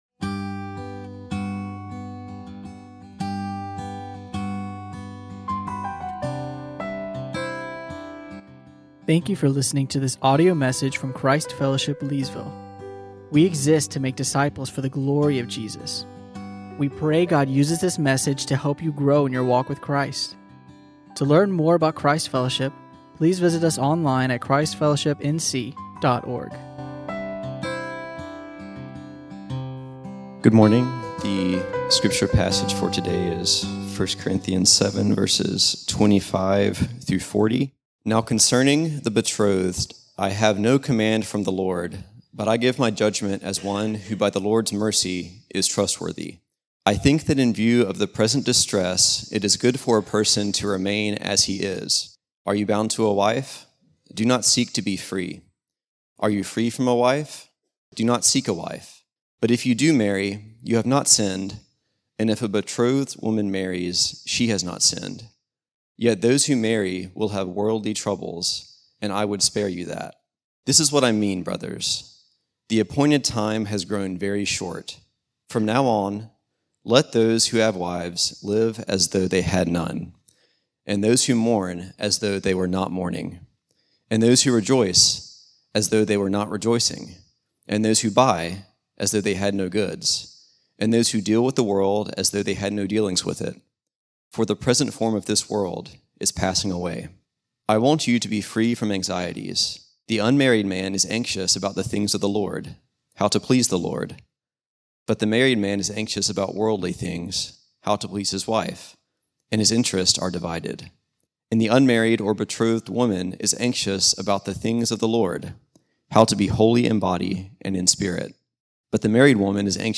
teaches on 1 Corinthians 7:25-40.